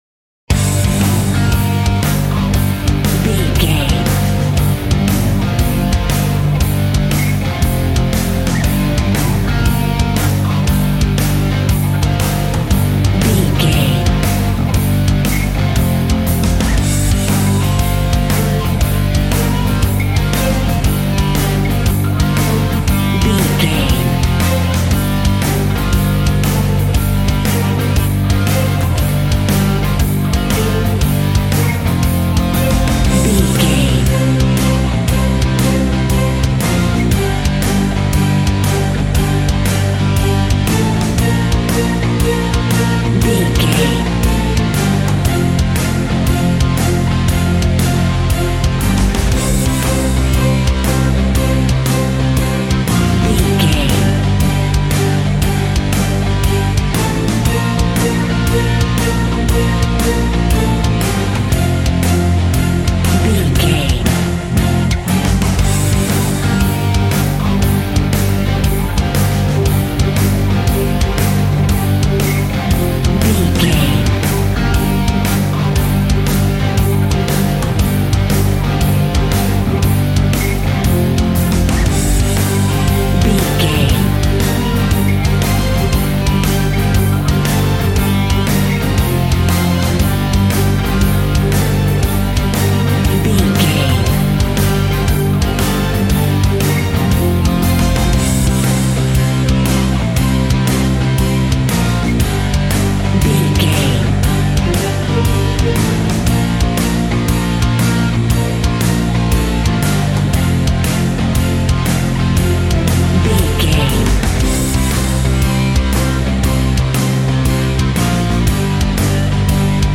Aeolian/Minor
angry
aggressive
electric guitar
drums
bass guitar